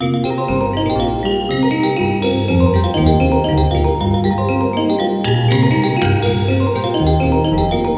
Balinese Bali)
パーカーッションにSC-88を
使った以外はTrinityの音です。